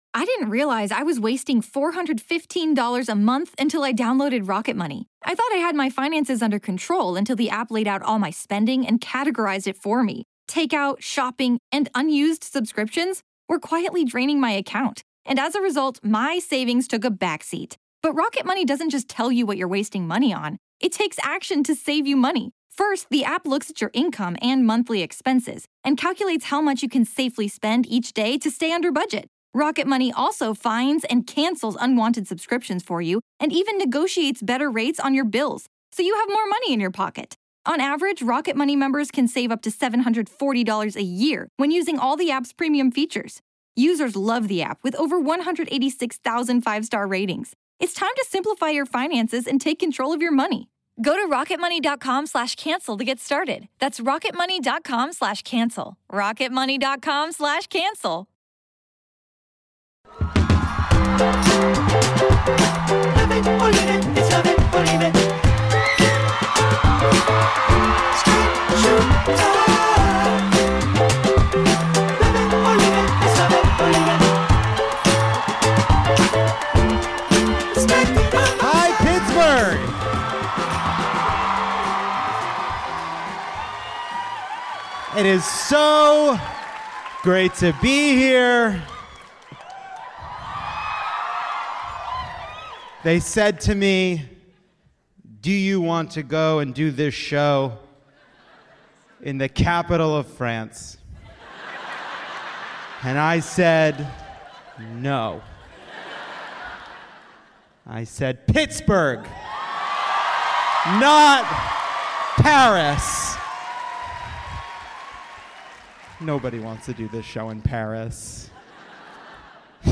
recorded live in Pittsburgh, PA.